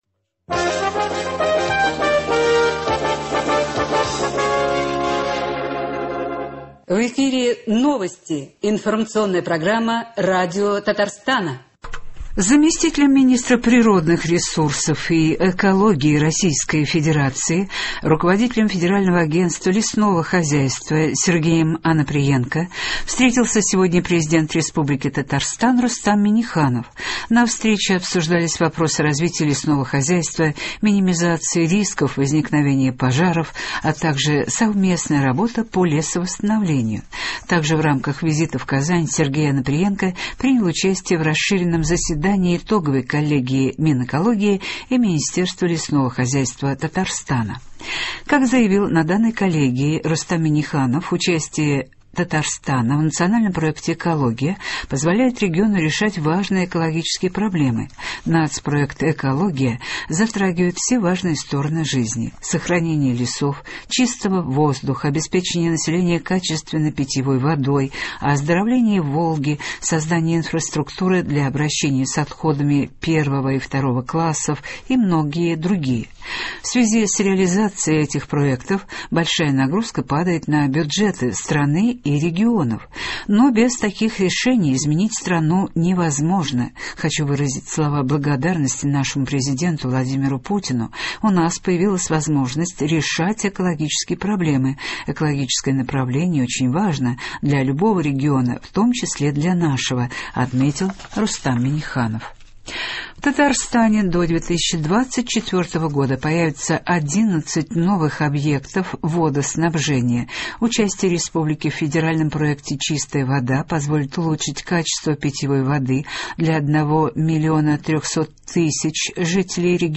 Новости. 14 января.